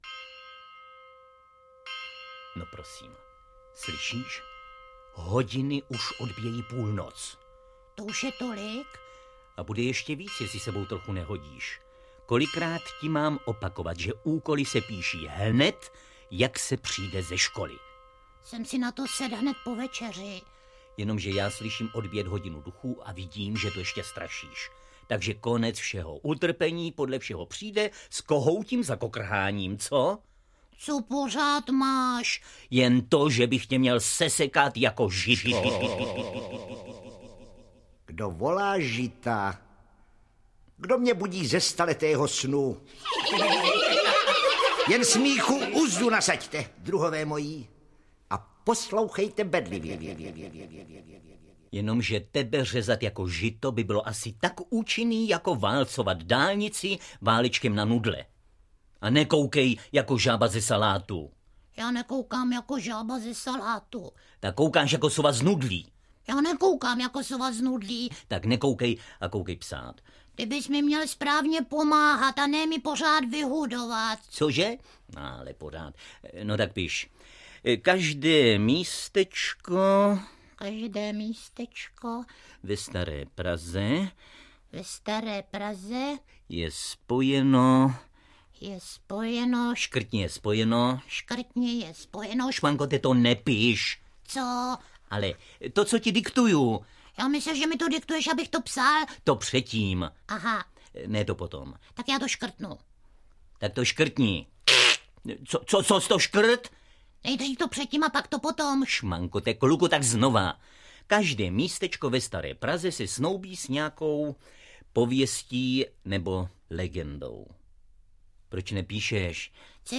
AudioKniha ke stažení, 1 x mp3, délka 44 min., velikost 40,7 MB, česky